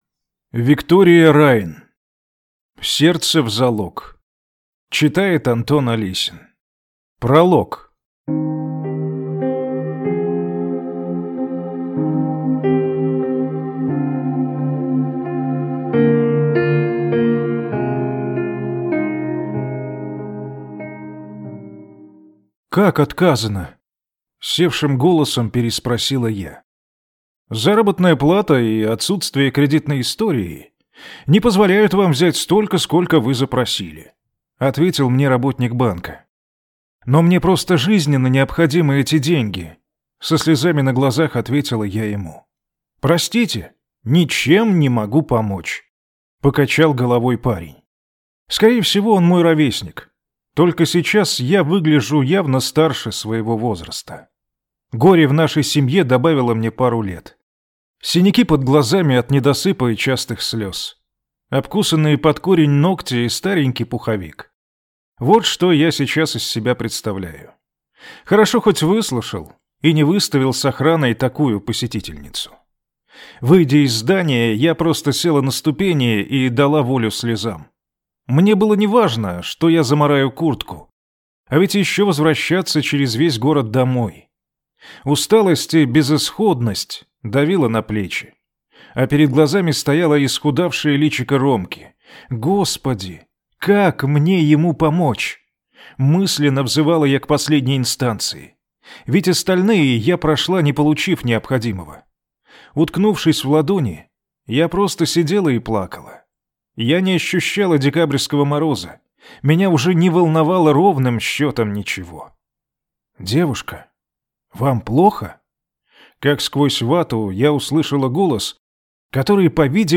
Аудиокнига Сердце в залог | Библиотека аудиокниг
Прослушать и бесплатно скачать фрагмент аудиокниги